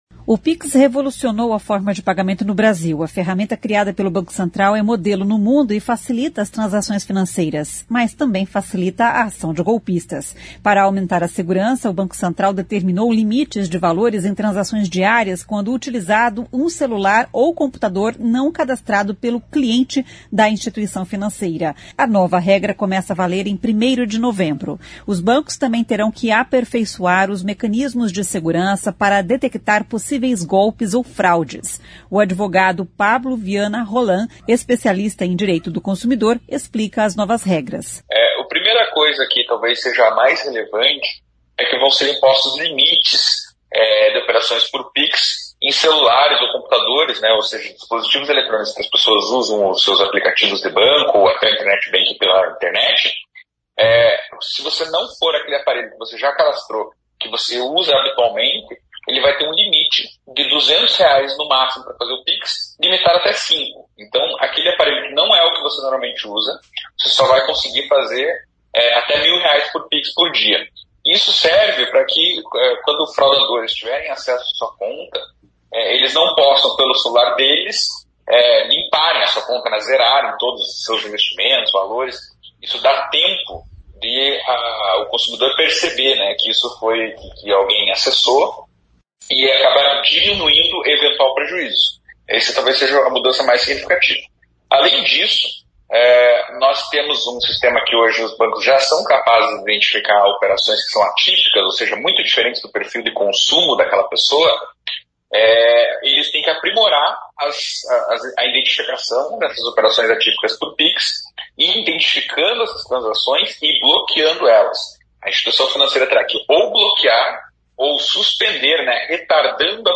especialista em Direito do Consumidor, explica as novas regras.